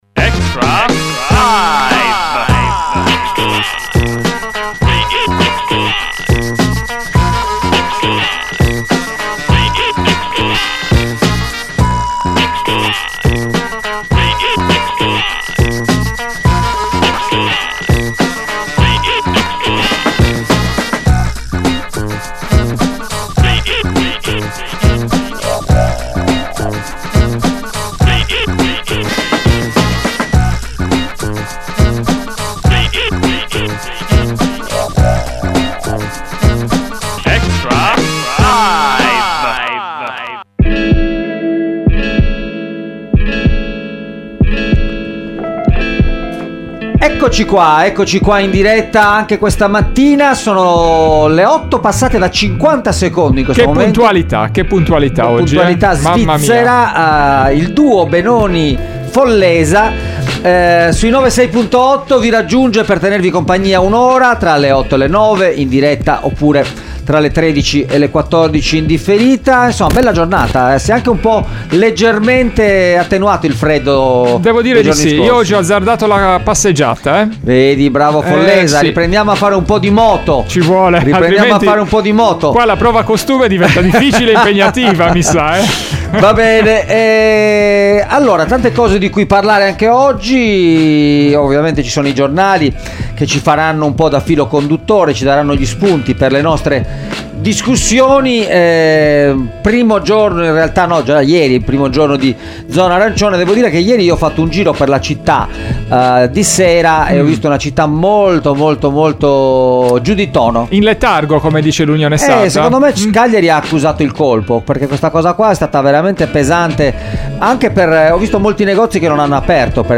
Extralive mattina: ogni giorno in diretta dalle 8 alle 9 e in replica dalle 13, il commento alle notizie di giornata dalle prime pagine dei quotidiani con approfondimenti e ospiti in studio.